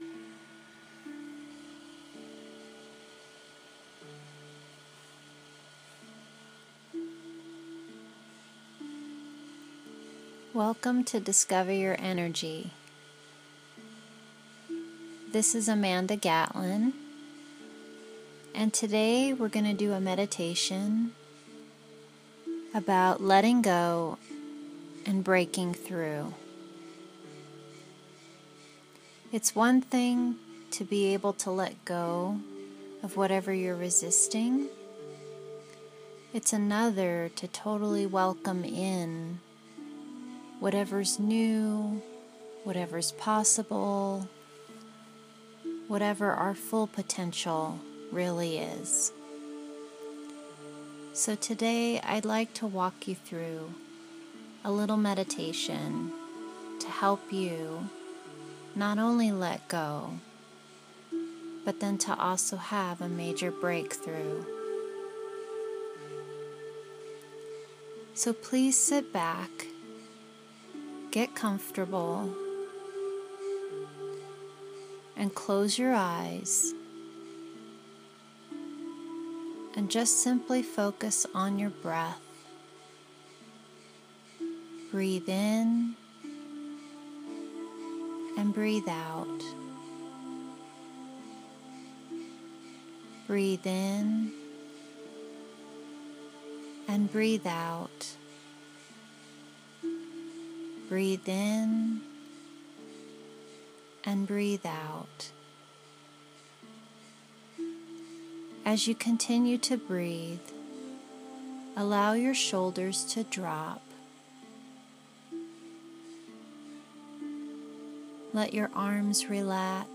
Letting Go Meditation - Discover Your Energy
Let-Go-And-Break-Through-Meditation.m4a